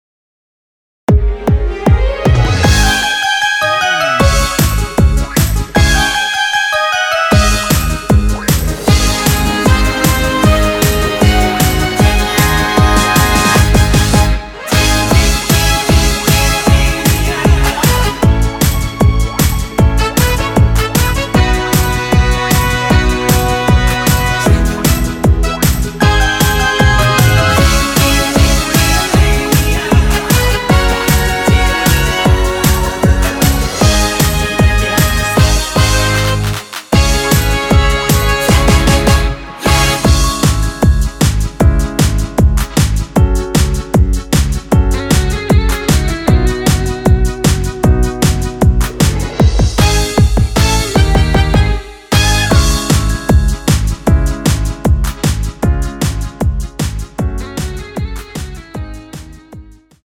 원키에서(-1)내린 코러스 포함된 MR입니다.
Dbm
앞부분30초, 뒷부분30초씩 편집해서 올려 드리고 있습니다.
중간에 음이 끈어지고 다시 나오는 이유는